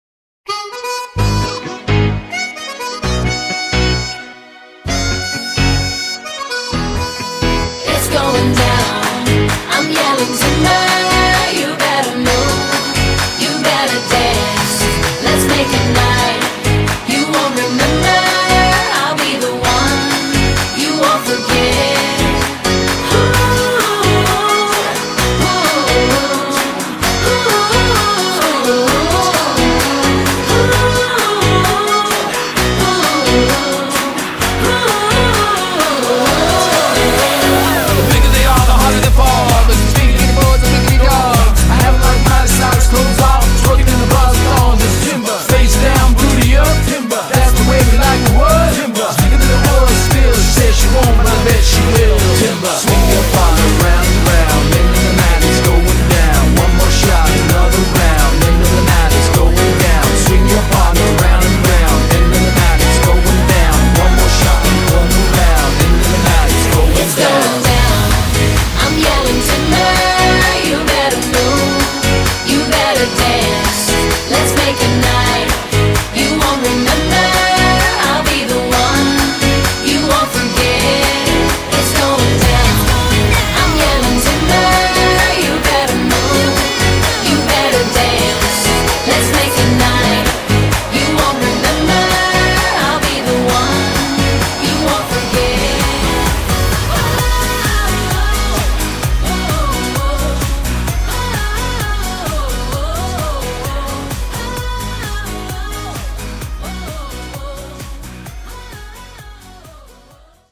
Top 40 contemporary dance band.